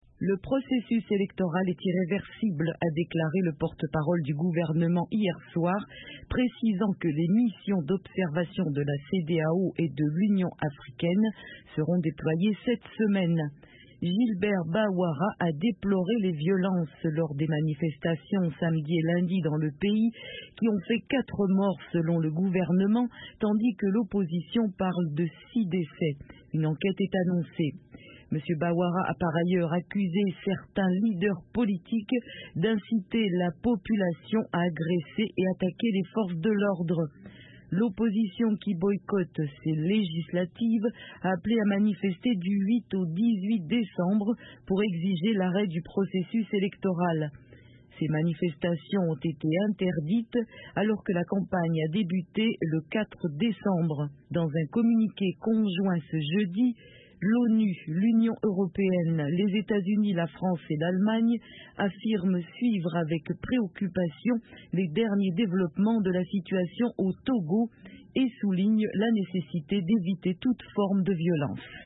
Reportage sur le maintien des Législatives au 20 décembre